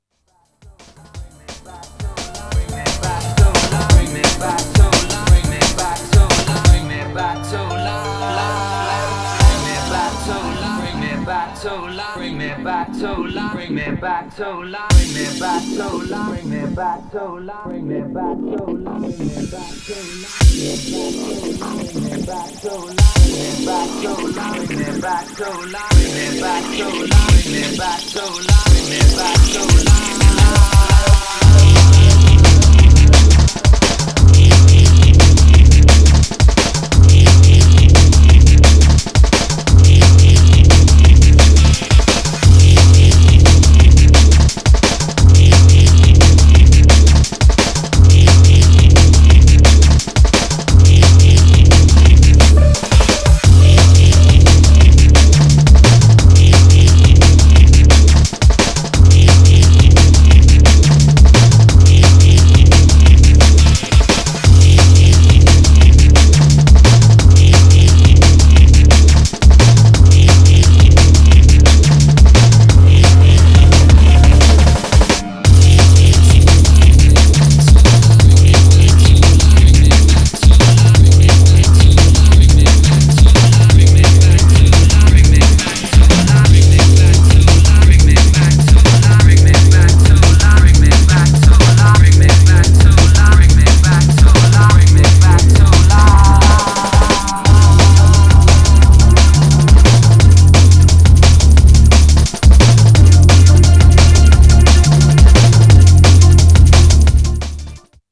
Styl: Drum'n'bass, Lounge, Breaks/Breakbeat